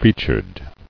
[fea·tured]